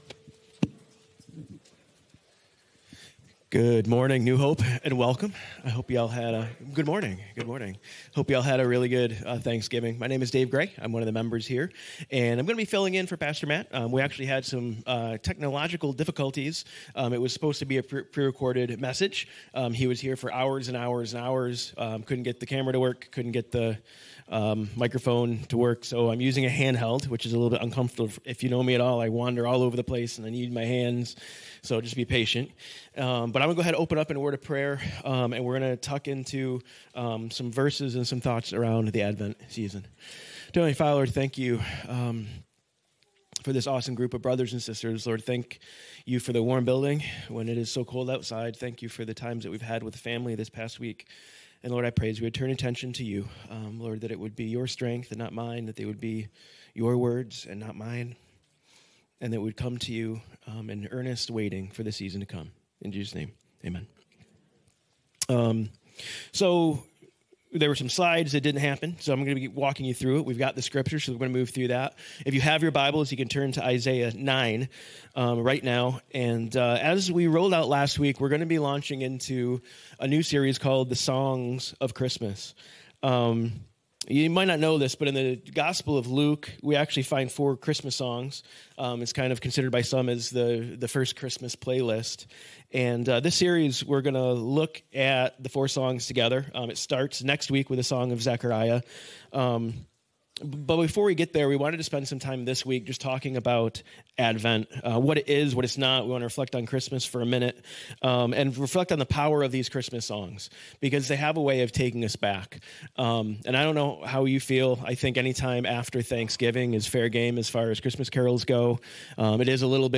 Sermons | New Hope Church